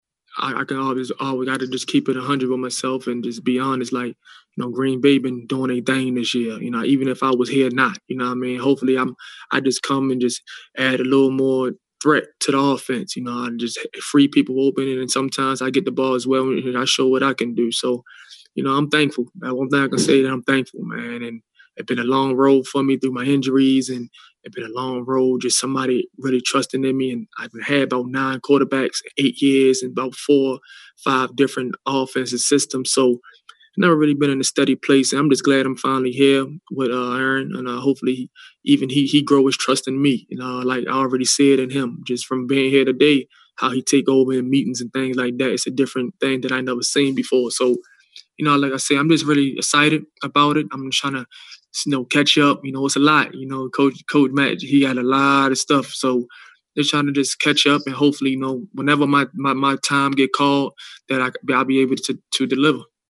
When Davante Adams pushed hi media availability back one day, in stepped Austin to talk about how grateful he is the Packers are giving him another chance and he’s anxious to find a role in Matt LaFleur’s offense, Tavon’s former offensive coordinator in Los Angeles in 2017.